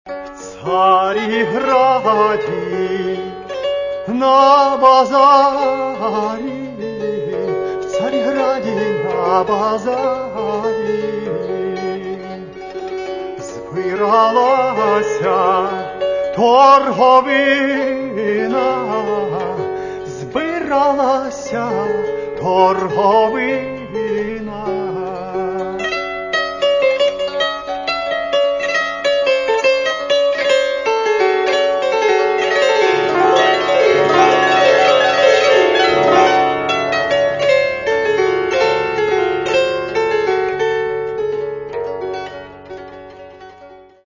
Каталог -> Народна -> Бандура, кобза тощо
Балада